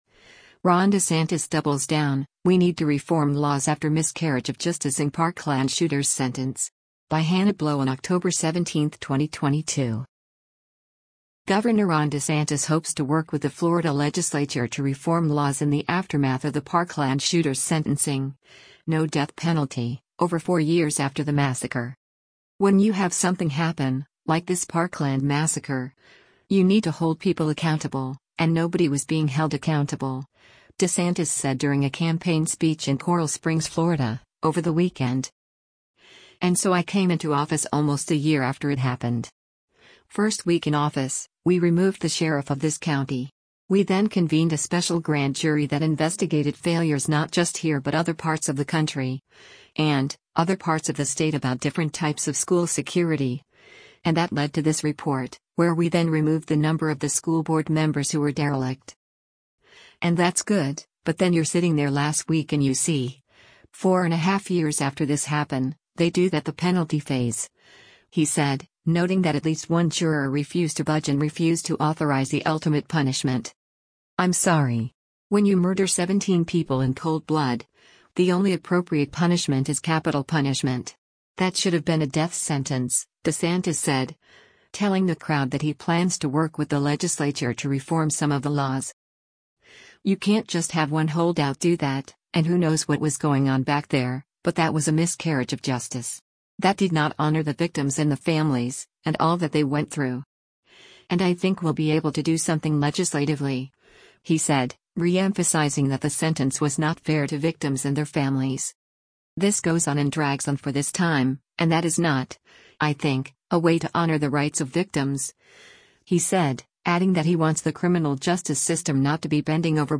“When you have something happen, like this Parkland massacre, you need to hold people accountable, and nobody was being held accountable,” DeSantis said during a campaign speech in Coral Springs, Florida, over the weekend: